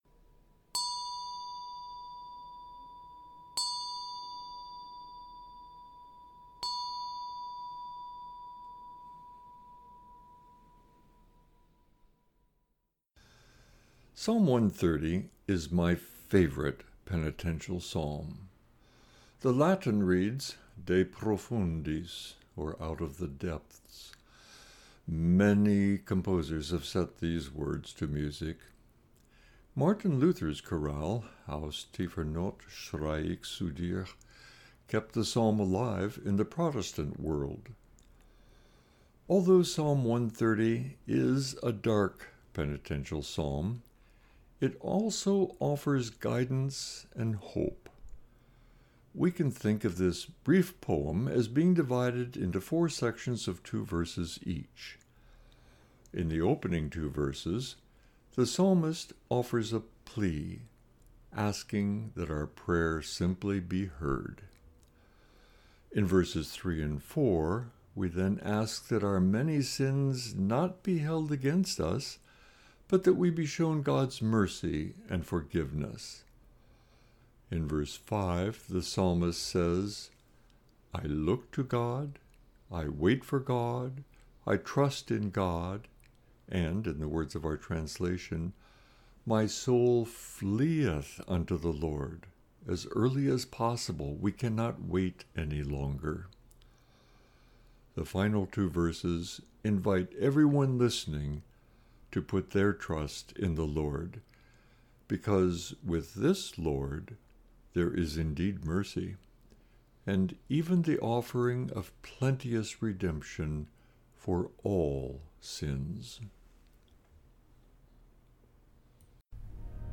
Meditation - Point Grey Inter-Mennonite Fellowship